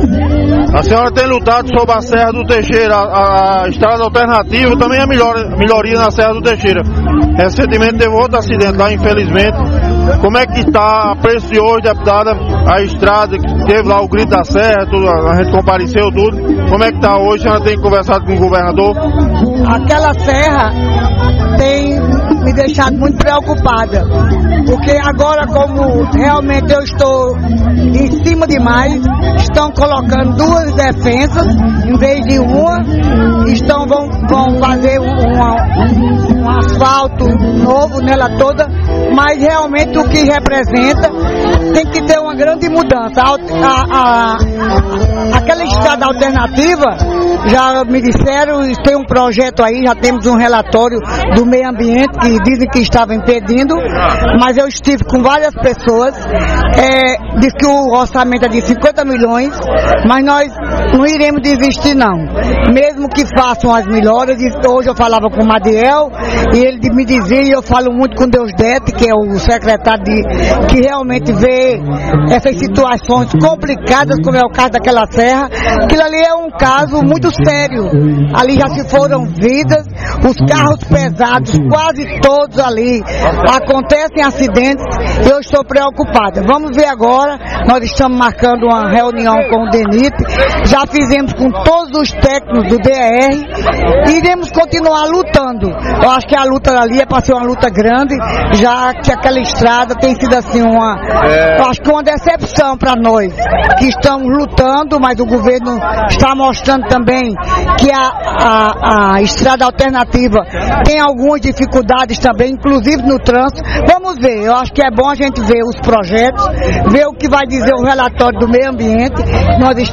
O evento contou com a presença do prefeito do município, Nabor Wanderley e da deputada estadual Francisca Motta.
Na oportunidade, a deputada Francisca Motta foi questionada acerca do projeto da estrada alternativa à Serra do Teixeira.